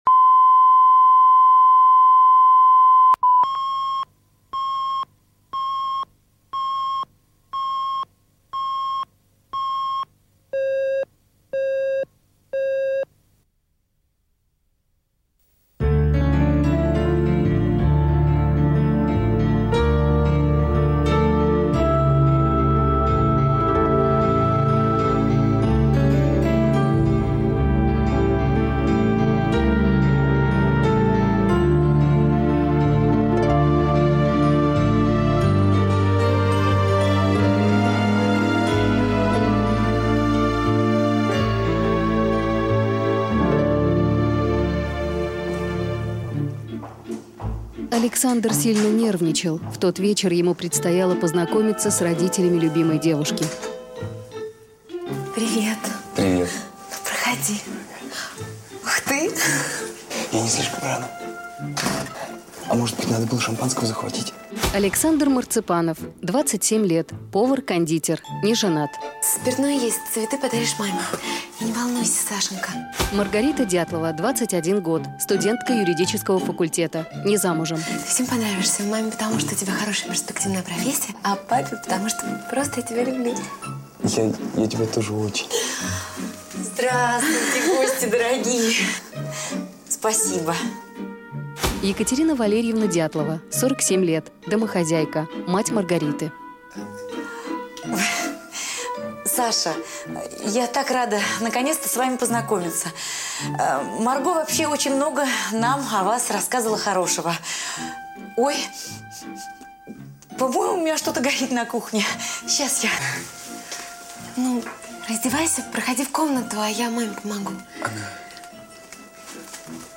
Aудиокнига Любимый повар Автор Александр Левин. Прослушать и бесплатно скачать фрагмент аудиокниги